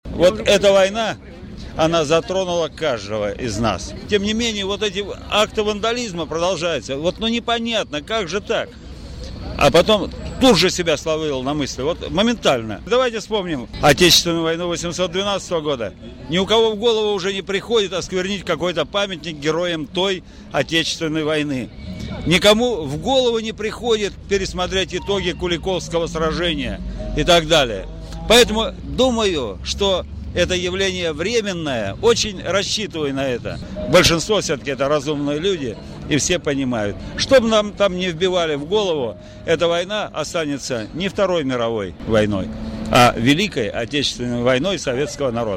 Такое мнение корреспонденту ИА «СеверИнформ» высказал Спикер городского парламента Игорь Степанов.
Игорь Степанов рассказывает об осквернении памятников